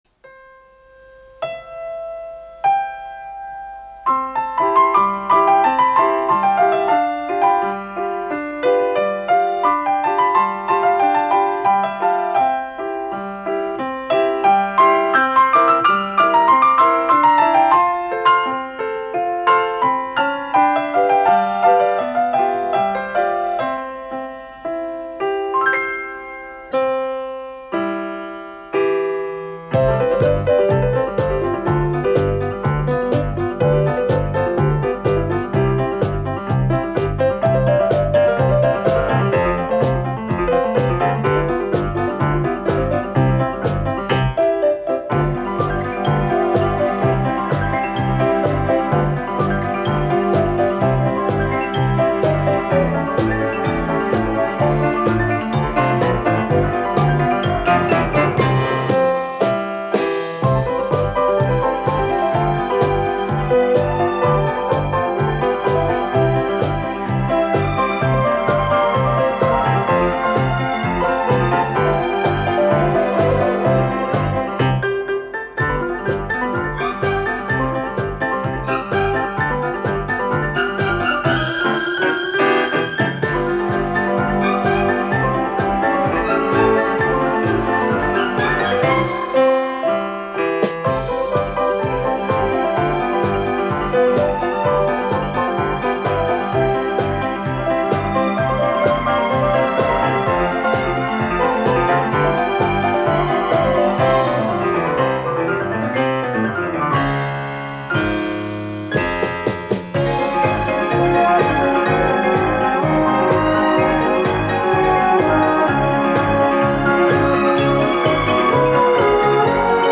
там ещё фортепиано солирует